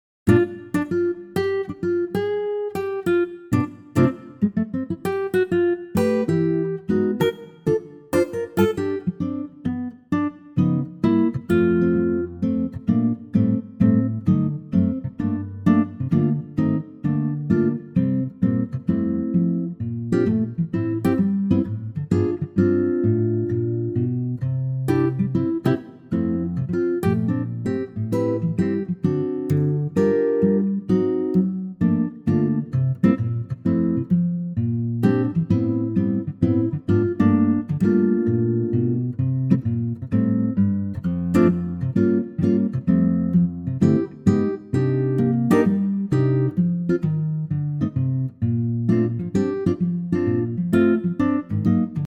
key - F - vocal range - C to C